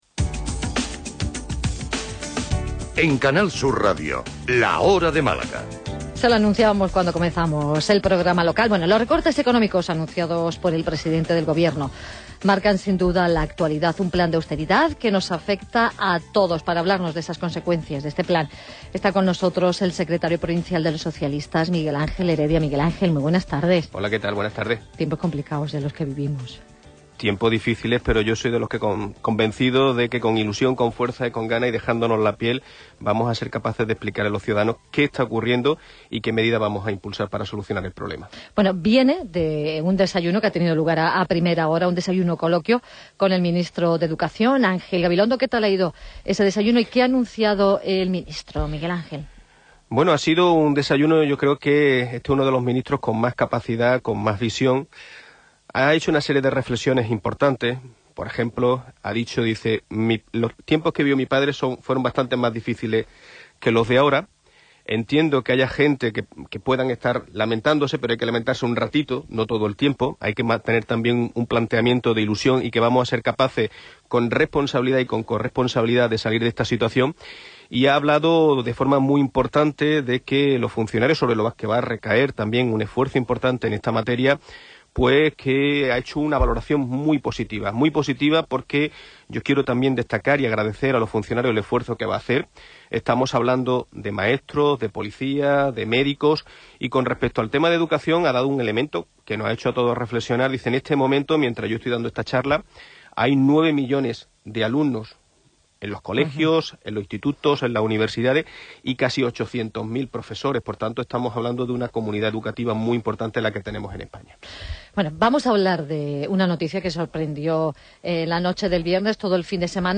Os ofrecemos la entrevista íntegra en formato Mp3.